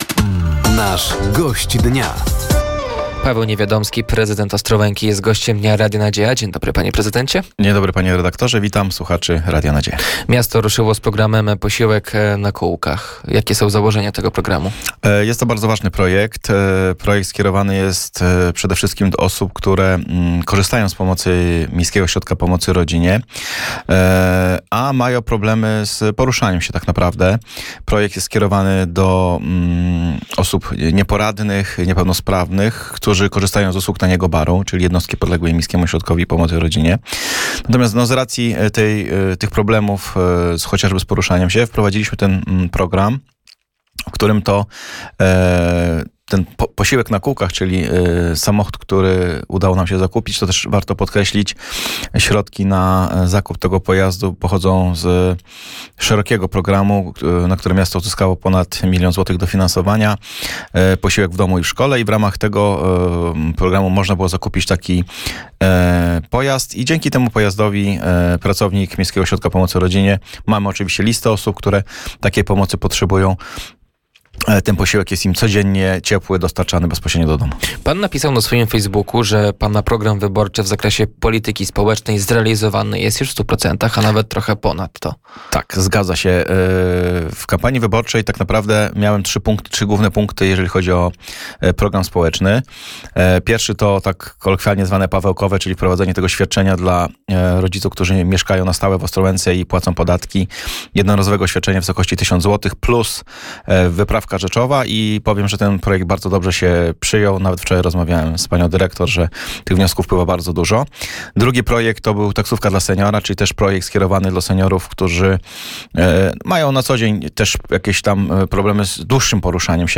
Gościem Dnia Radia Nadzieja był prezydent Ostrołęki Paweł Niewiadomski. Tematem rozmowy był program „Posiłek na kółkach”, nowy budynek Zakładu Pielęgnacyjno-Opiekuńczego i zagospodarowanie przestrzeni starego budynku oraz projekt „Zielona Ostrołęka”.